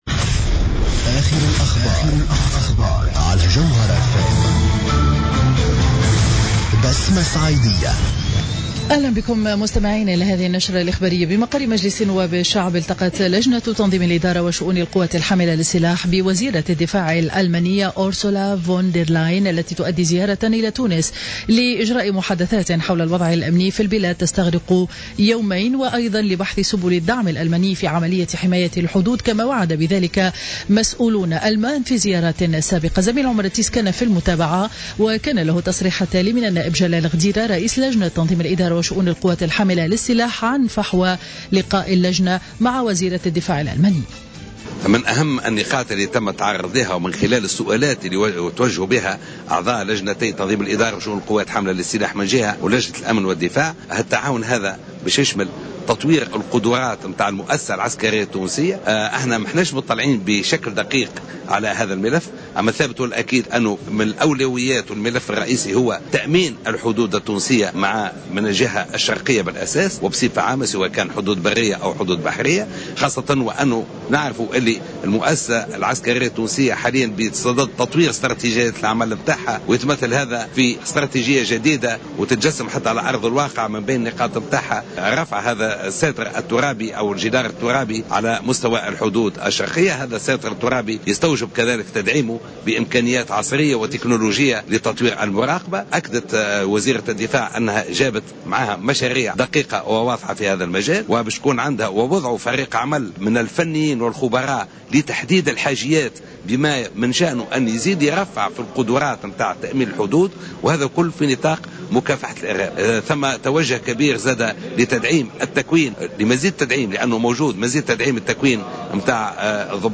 نشرة أخبار منتصف النهار ليوم الأربعاء 29 جويلية 2015